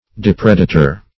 Search Result for " depredator" : The Collaborative International Dictionary of English v.0.48: Depredator \Dep"re*da`tor\, n. [L. depraedator.]